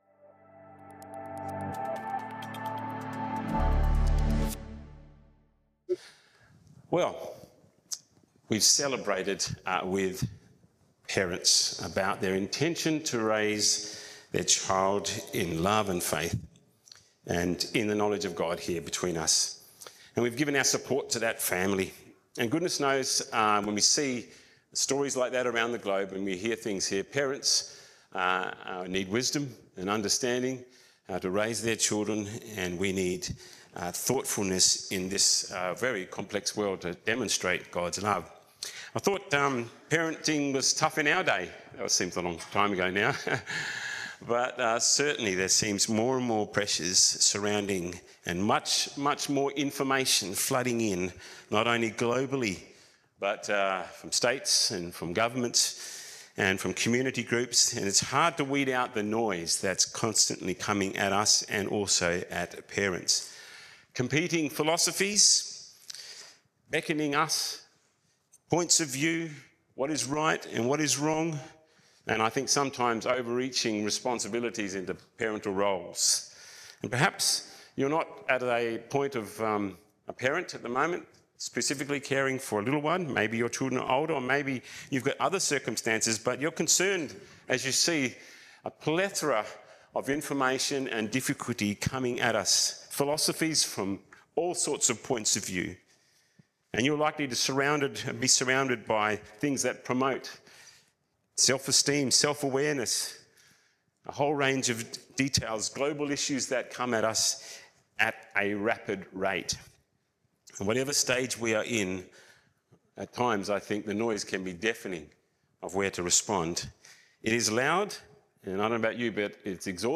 Sermons - Como Baptist Church